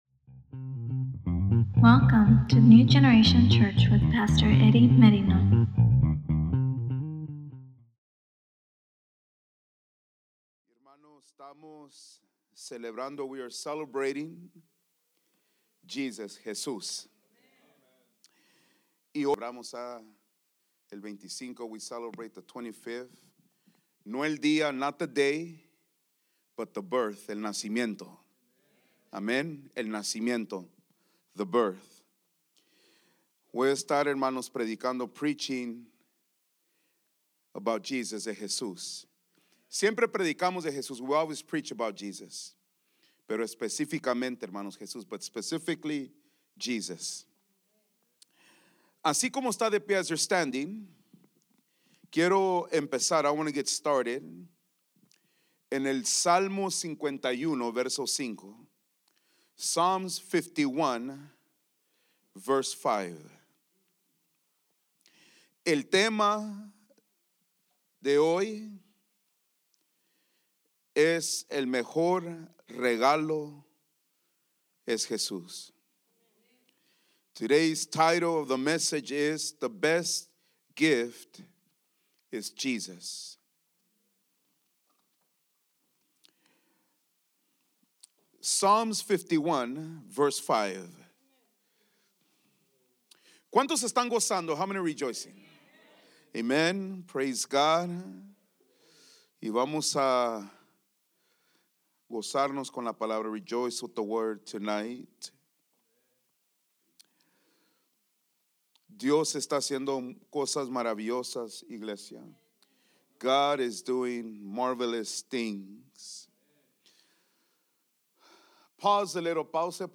at Chiltern Kills crime fest 2024
at the Chiltern Kills crime writing festival 2024: We'd Like A Word